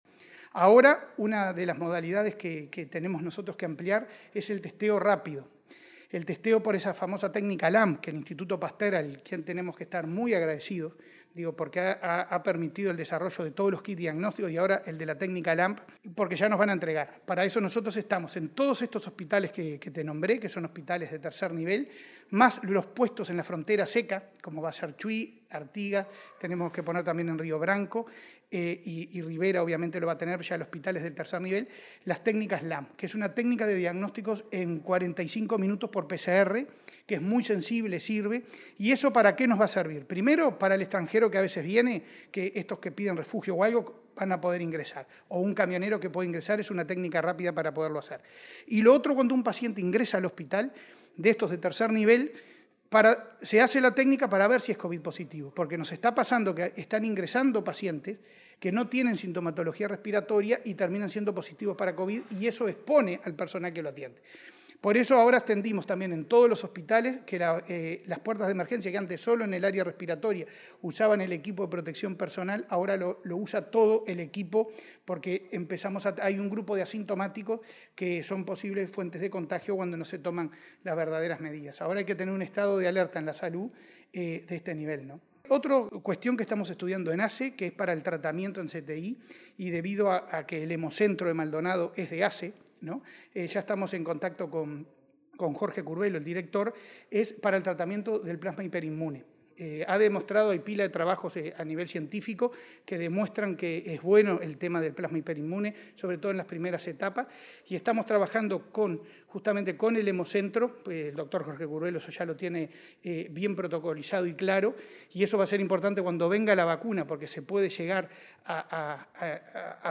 Cipriani en entrevista con Comunicación Presidencial sobre uso de test LAMP y plasma